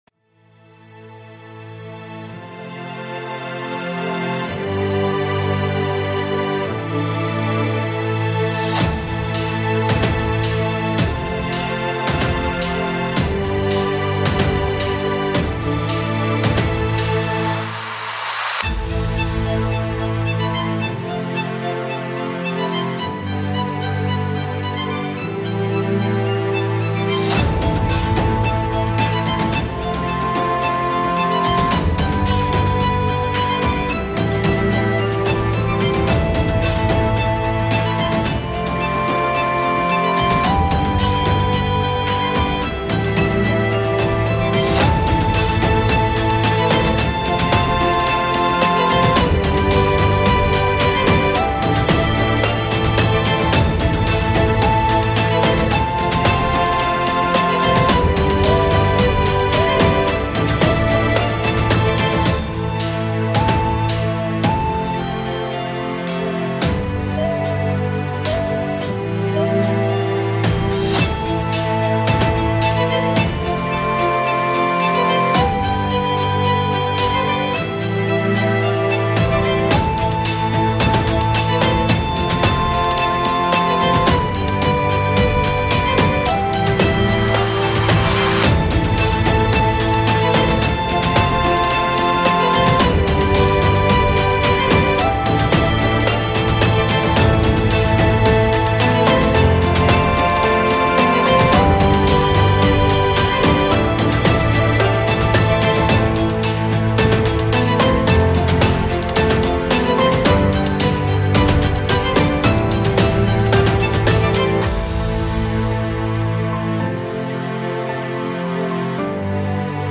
;) Anyway, have a orchestral track for that hapiness.